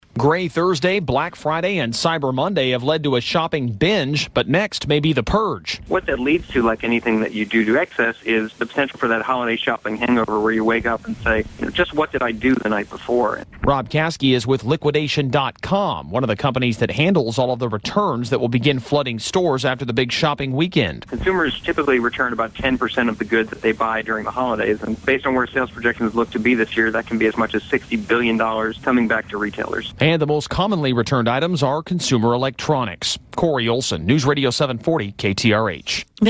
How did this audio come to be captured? KTRH News Radio Houston, Texas — December 2, 2013